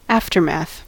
aftermath: Wikimedia Commons US English Pronunciations
En-us-aftermath.WAV